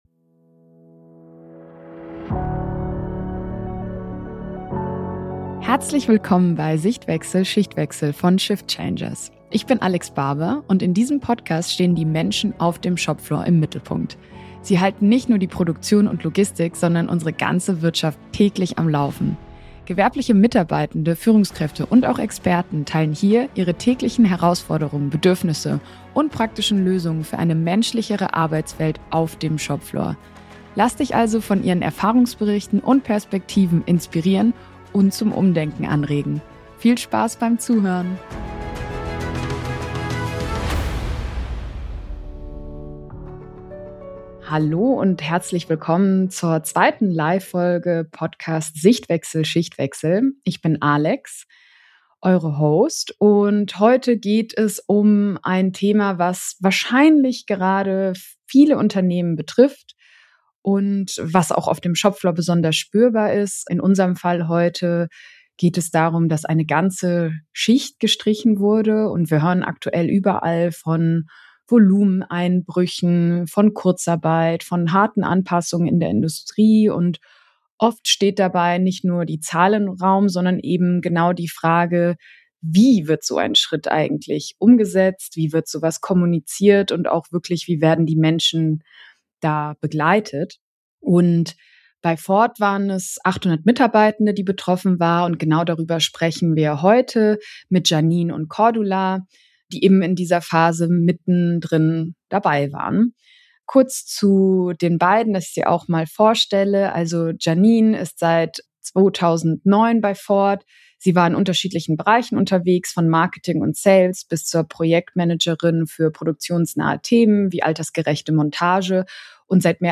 Rund 800 Mitarbeitende müssen innerhalb von nur drei Monaten das Unternehmen verlassen. In dieser zweiten Live-Podcast-Folge sprechen wir offen und praxisnah darüber, wie dieser Prozess gestaltet wurde: menschlich, empathisch und ohne eine einzige betriebsbedingte Kündigung.